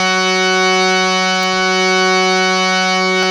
52-key09-harm-f#3.wav